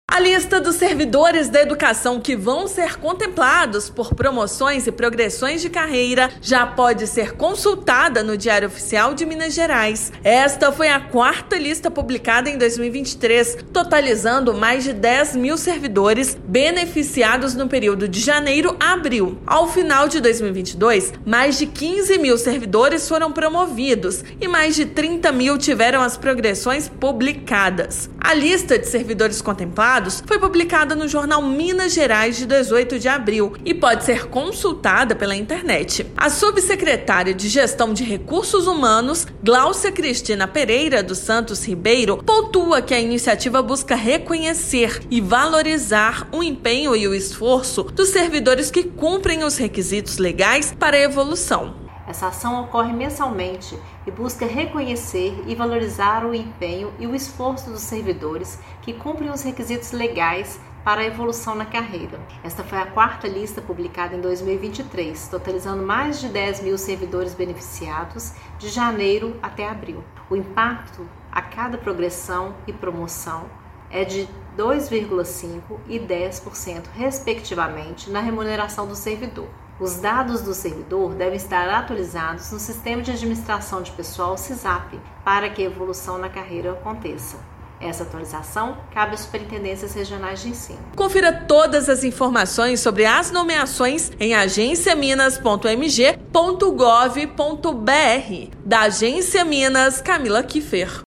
[RÁDIO] Governo de Minas concede evolução de carreira a mais de 10 mil servidores da educação em 2023
Neste ano, mais de 5 mil servidores tiveram a progressão concedida e outros 4,5 mil já foram promovidos. Ouça a matéria de rádio.